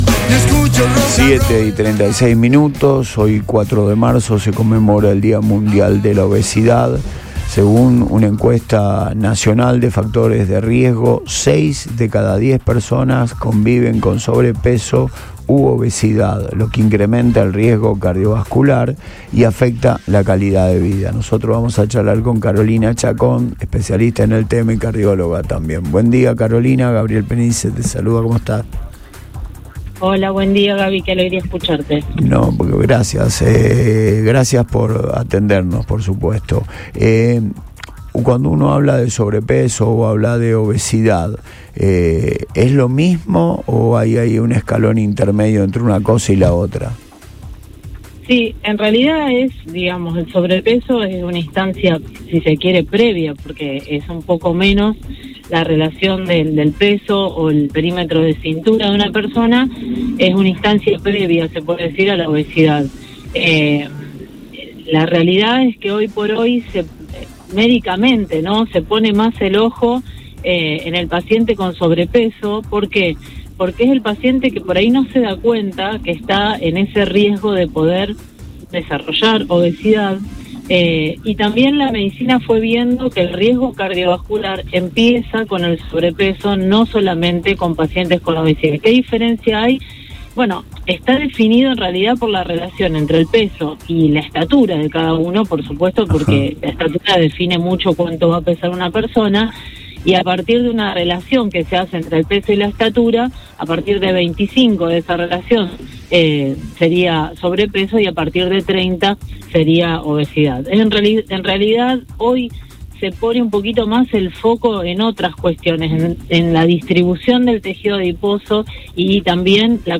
En una charla en Radio Boing, la médica explicó que la medicina actual cambió el foco: ya no se espera a que el paciente sea obeso para intervenir, sino que se busca alertar a quienes tienen sobrepeso, ya que el peligro para el corazón se activa mucho antes de lo que se cree.